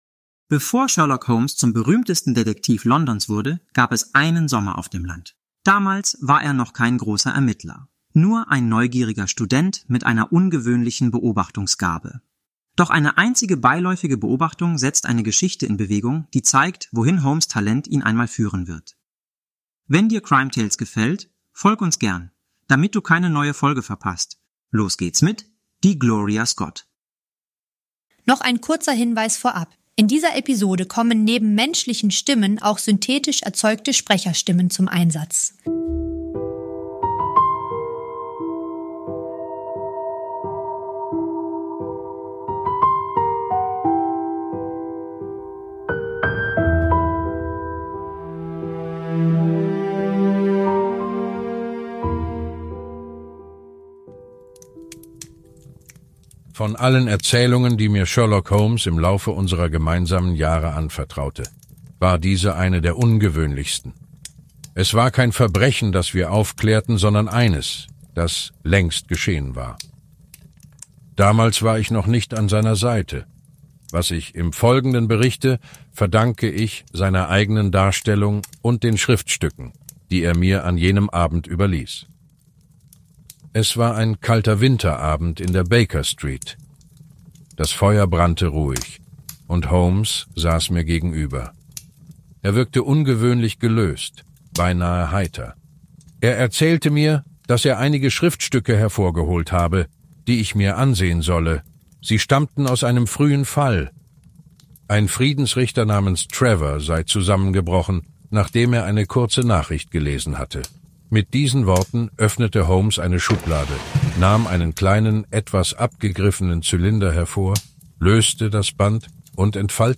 - In dieser Produktion kommen neben unseren eigenen Stimmen auch synthetische Sprecherstimmen zum Einsatz.